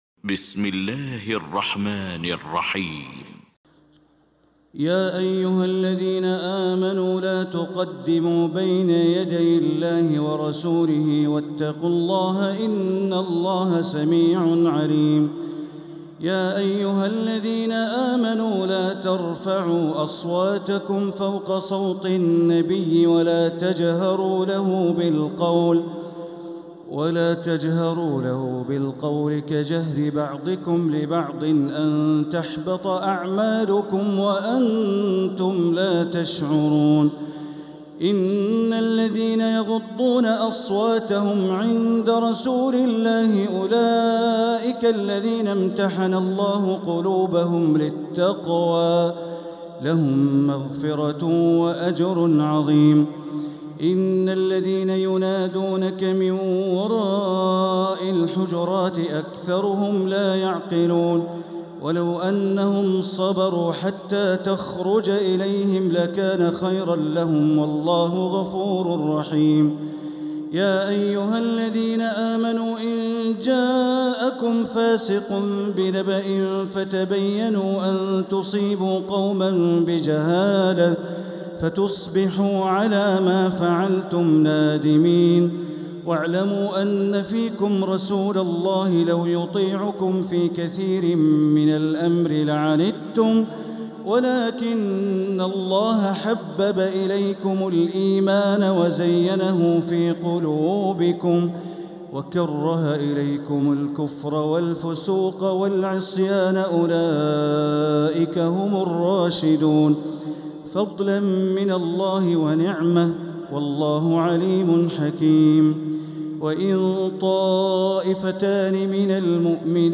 المكان: المسجد الحرام الشيخ: معالي الشيخ أ.د. بندر بليلة معالي الشيخ أ.د. بندر بليلة الحجرات